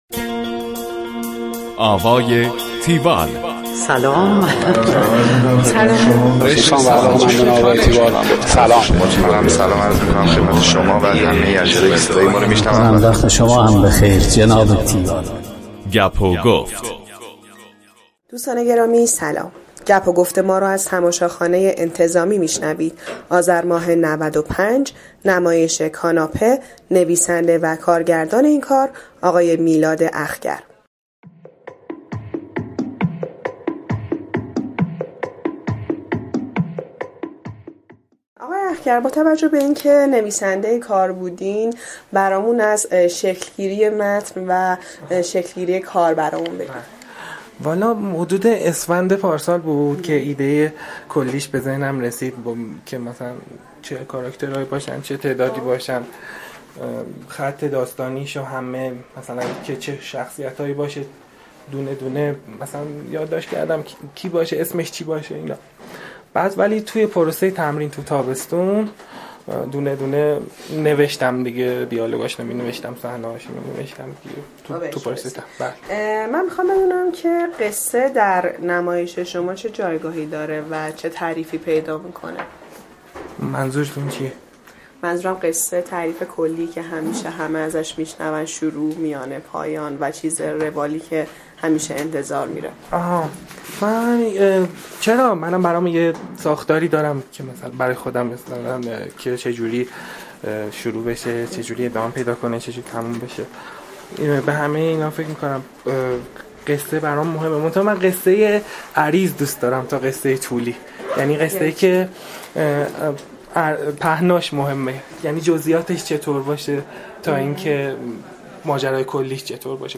جزئیات قصه در نمایش ما از کلیاتش مهم تر است گفتگوی تیوال